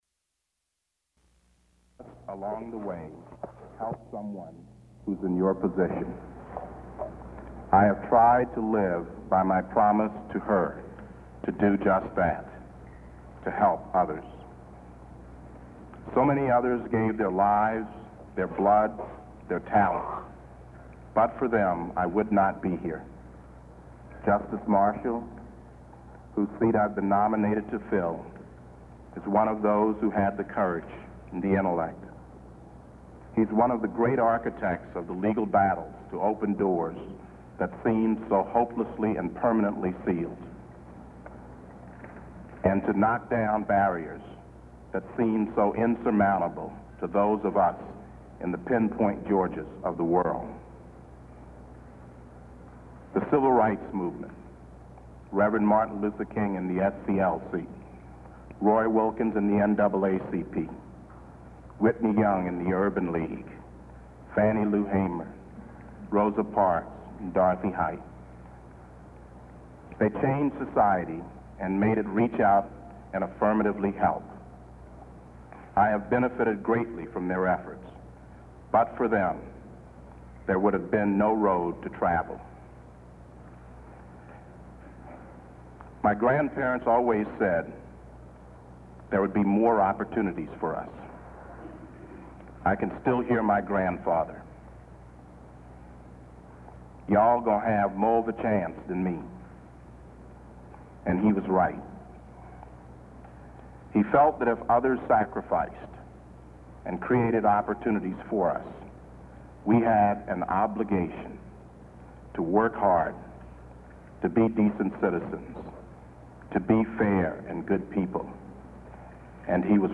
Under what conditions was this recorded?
Supreme Court Judges--Selection and appointment Natural law United States Material Type Sound recordings Language English Extent 00:43:56 Venue Note Broadcast on C-SPAN, Sept. 10, 1991.